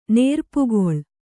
♪ nērpugoḷ